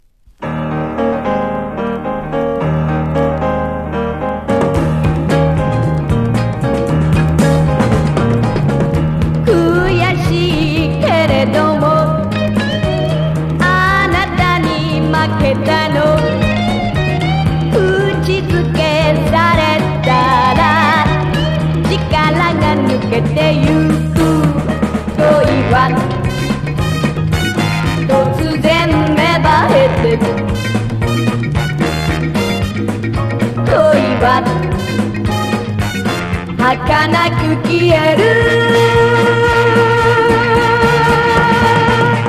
Sexy,Charm&Groovyガールズ歌謡鬼Killer盤！！！！！！